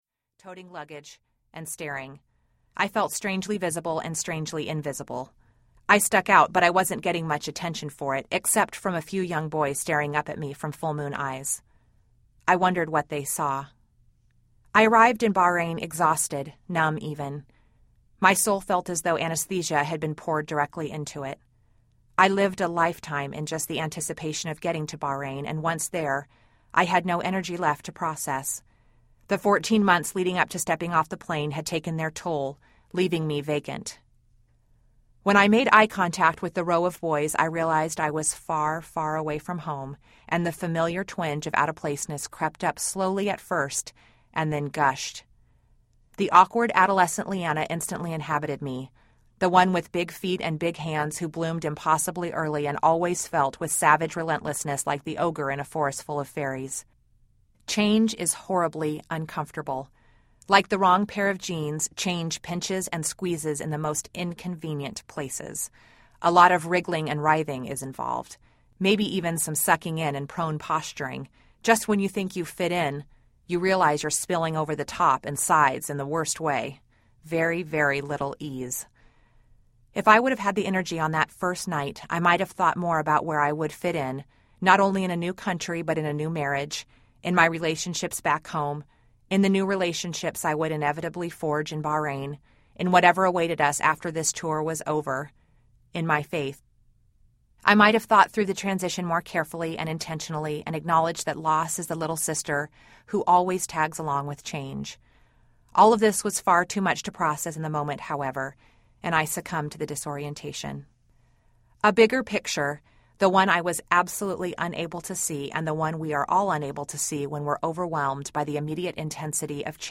Found Art Audiobook